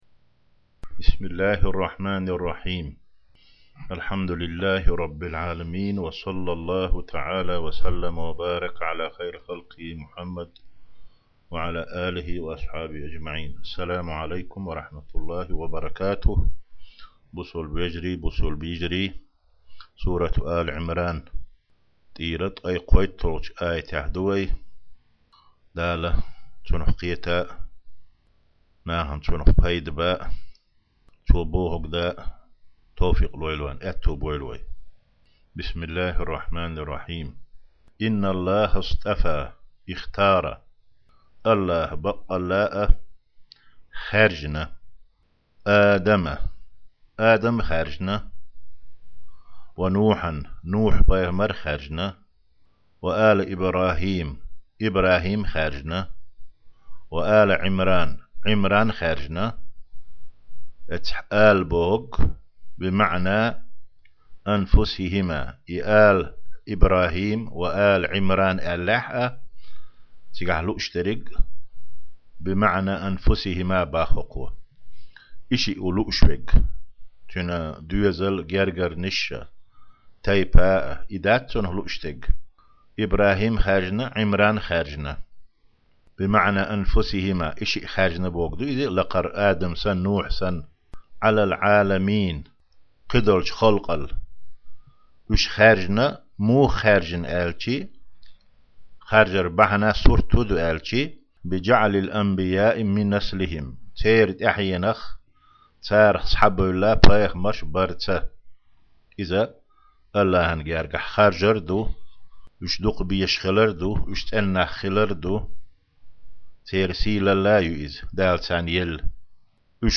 5-гIа дарс: Сурат Áли-Iимран 33-39 аят (Тафсирул-Жалалайн).